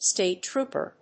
音節stàte tróoper